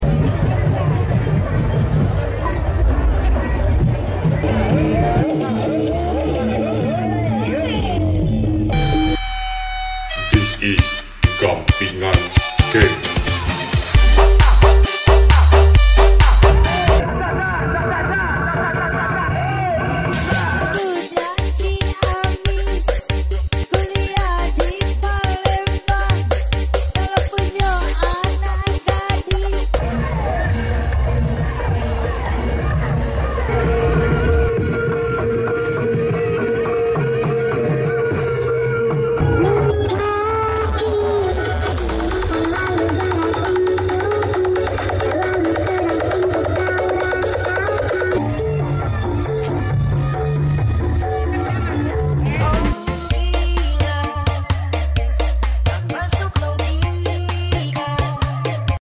Karnaval Desa Keboromo Tayu Pati